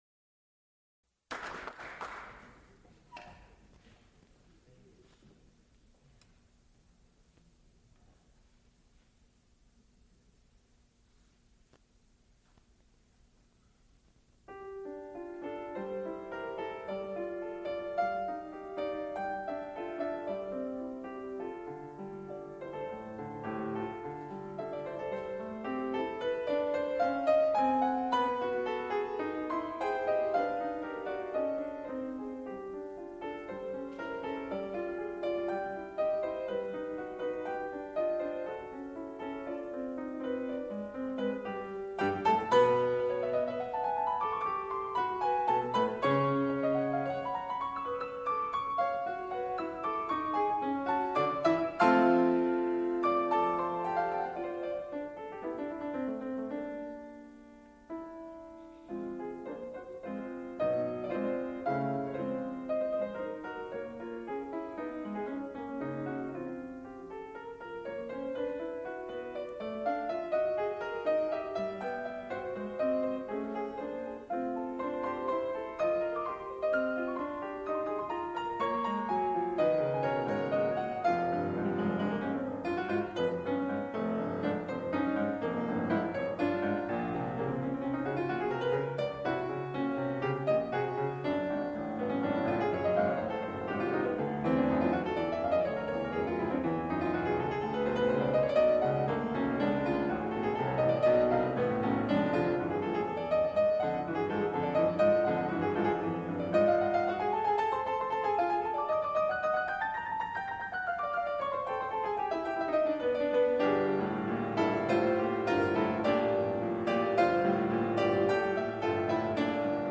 Recital of twentieth-century music | Digital Pitt
piano
baritone
Extent 4 audiotape reels : analog, quarter track, 7 1/2 ips ; 7 in.
Piano music
Songs (Medium voice) with piano